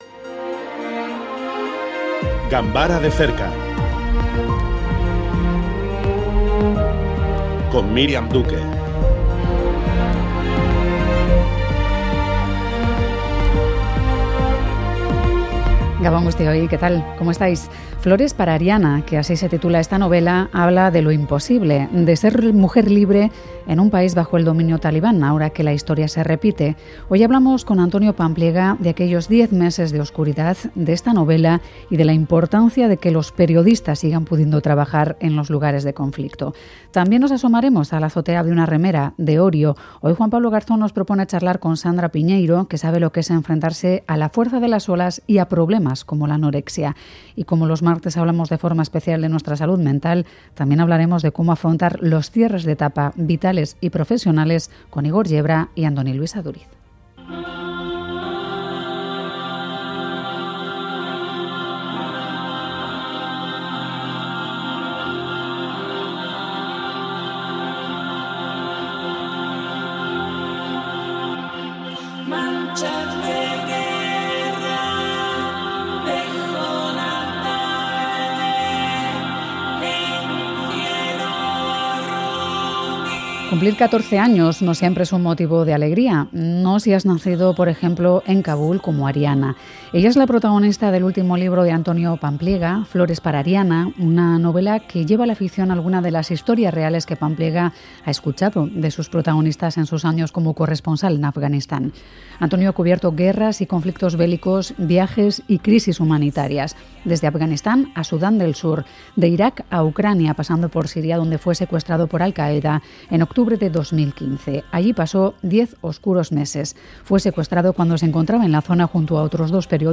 Audio: Hablamos con el periodista Antonio Pampliega de "Flores para Ariana", la novela que comenzó a escribir en un pequeño papel durante su secuestro en Siria